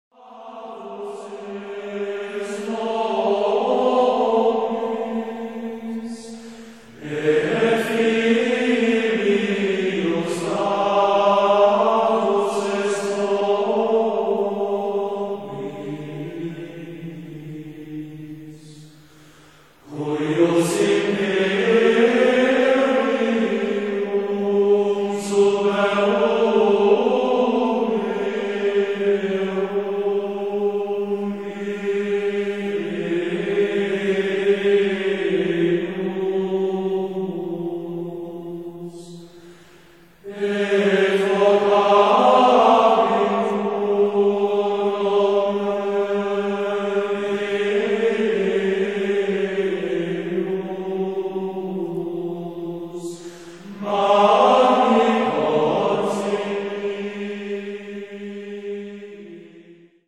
Рождественская Месса 1960 г. Запись сделана в монастыре св. Мартина Турского.